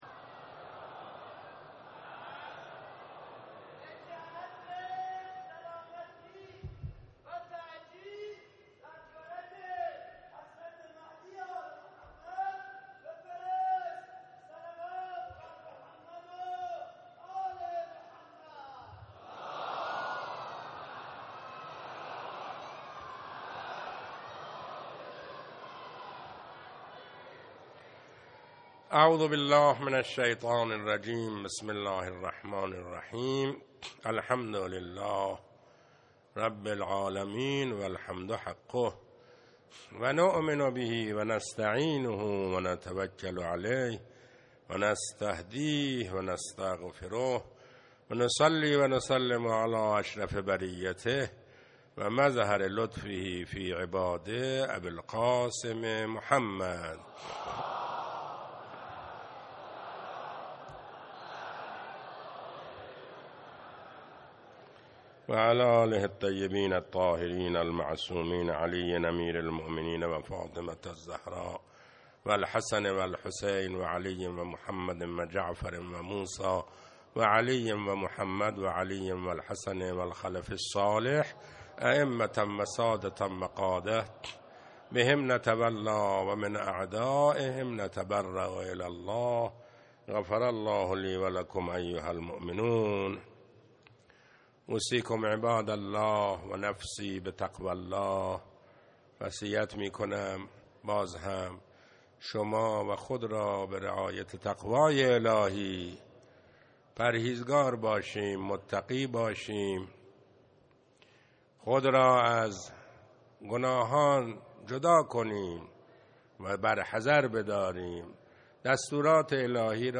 خطبه دوم